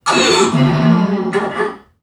NPC_Creatures_Vocalisations_Robothead [28].wav